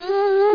00694_Sound_squeeze.mp3